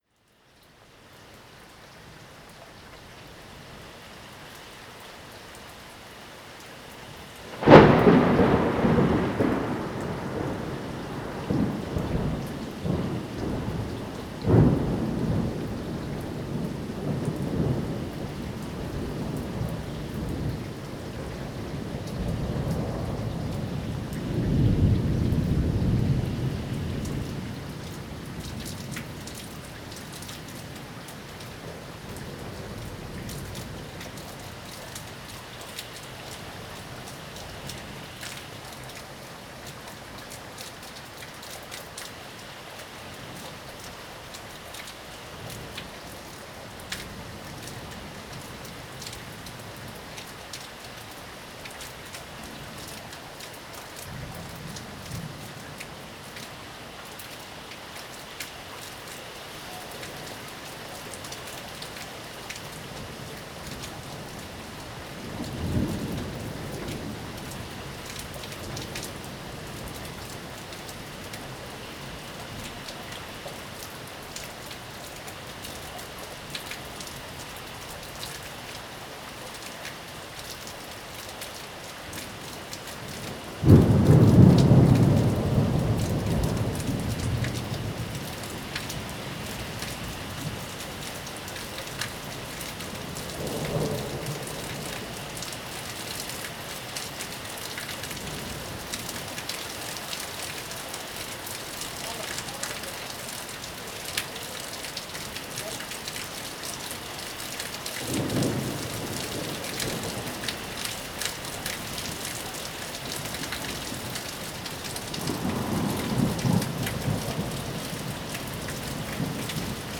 Pluie relaxante : des sons de la nature pour un sommeil profond, la me´ditation et la relaxation
Sons relaxants de la nature
Le doux bruit des gouttes de pluie qui s'abattent possède un effet relaxant sur notre corps.